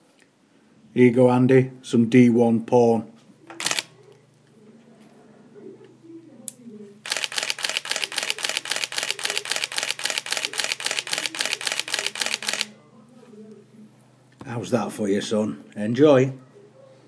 D1 shutter sound